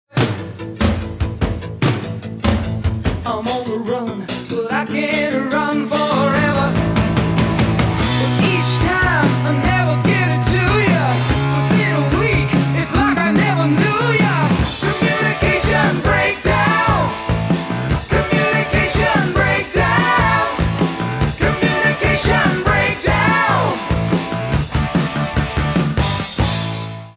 guitar, drums, piano, synthesizer, vibes, vocals
bass
Album Notes: Recorded at Mushroom Studios, Vancouver, Canada